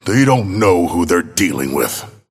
Abrams voice line - They don't know who they're dealing with.